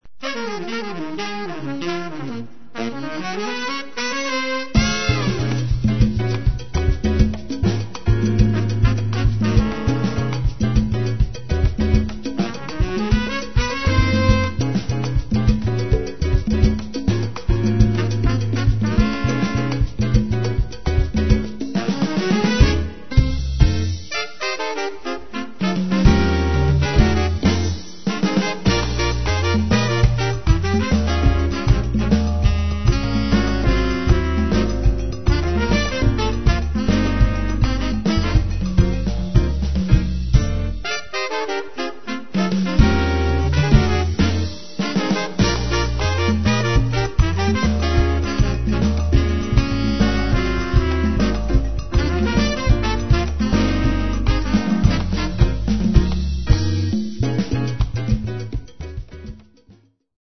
trumpet, flugelhorn, vocals
Tenor and soprano saxophones
drums, timbales and small percussion
congas, Afro-Venezuelan percussion, vocals, guiro and claves
baby bass